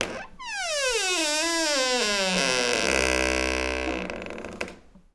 door_creak_long_03.wav